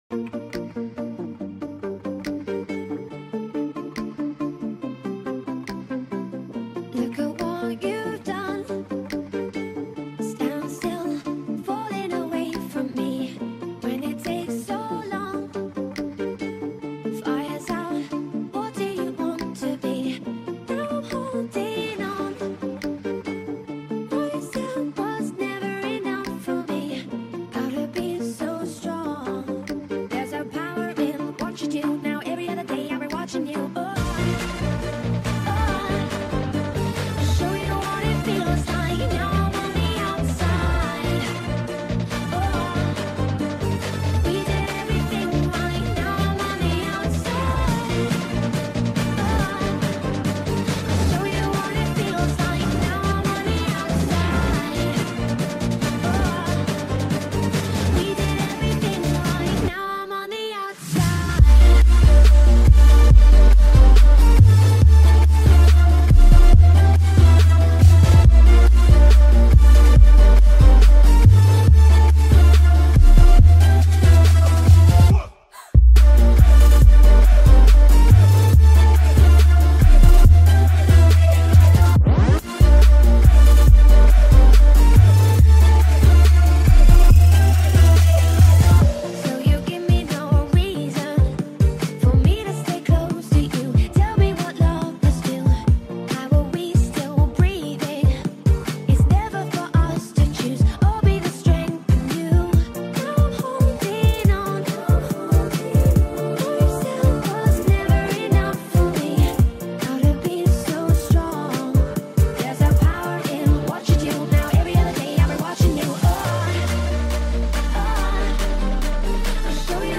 Trap Remix